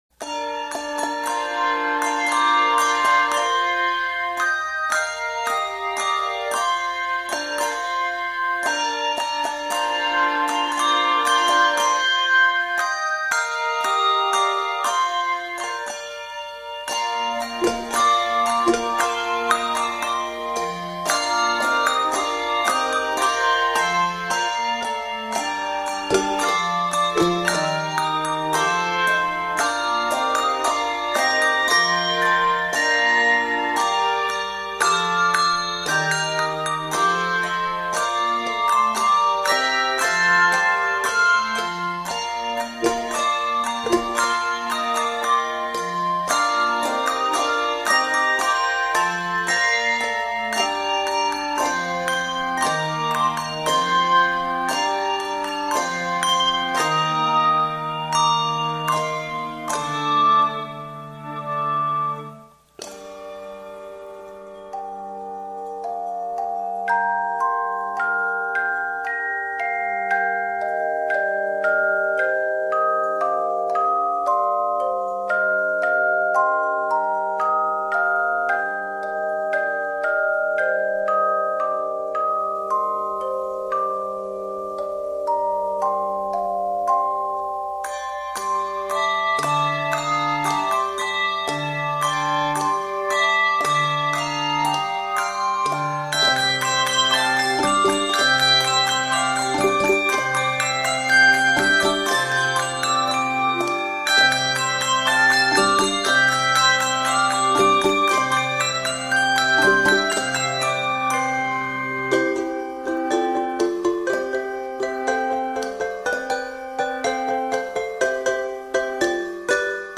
Easter medley